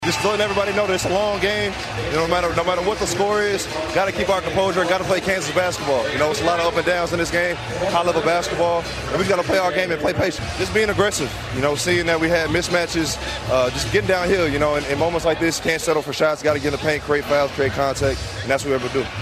He talked with ESPN after the game.